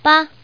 Index of /fujian_pw_test/update/3226/res/sfx/common_woman/